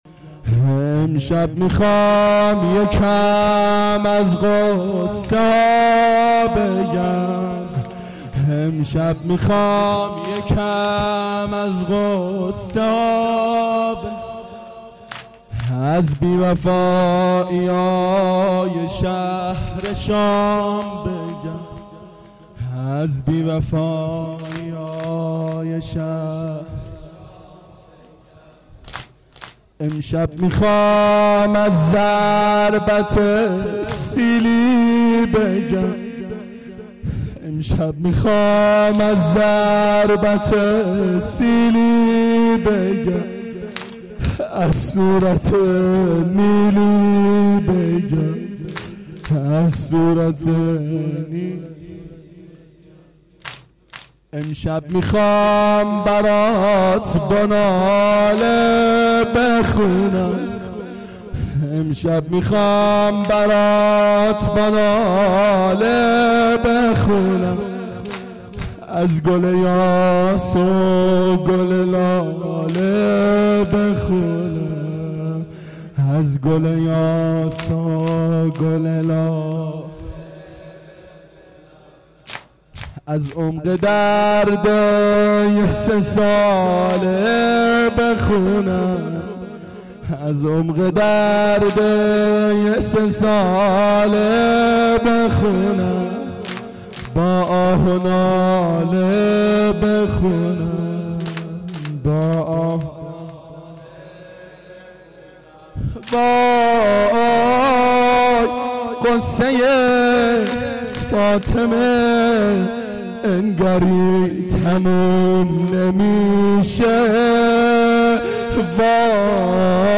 شب سوم محرم 88 گلزار شهدای شهر اژیه